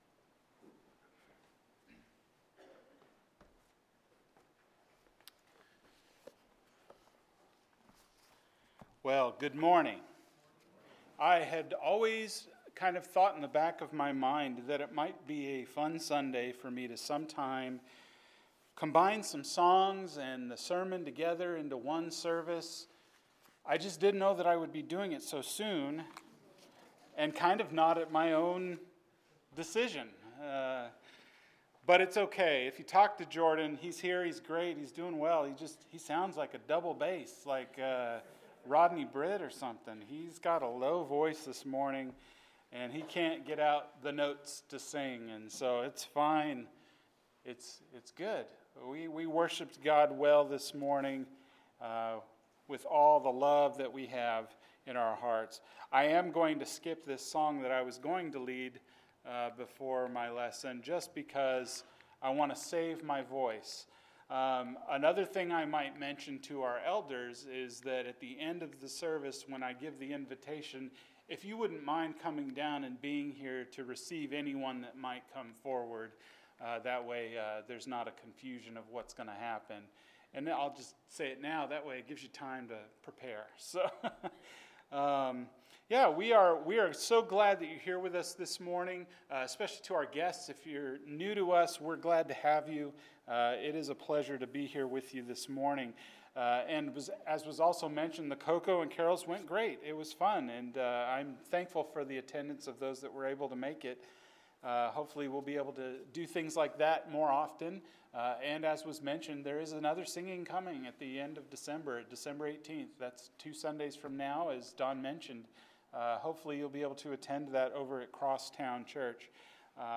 Seven – Forsaken? – Sermon